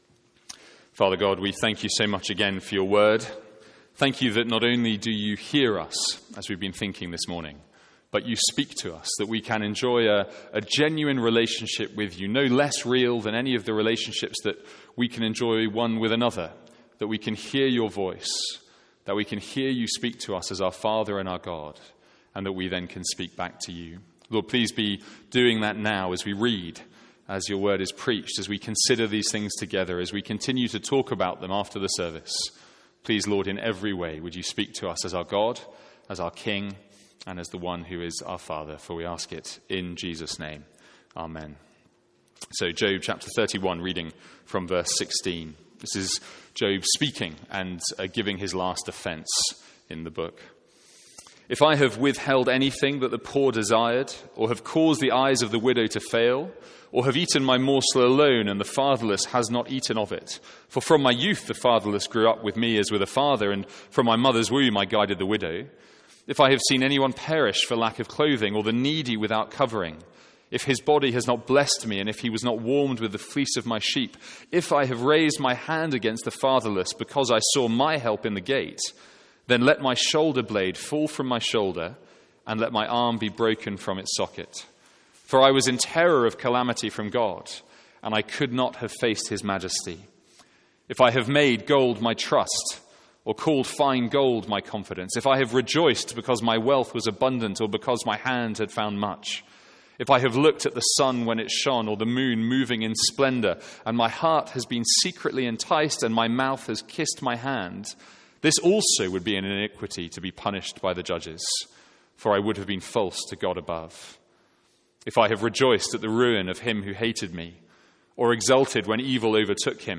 Sermons | St Andrews Free Church
From our morning series in Job.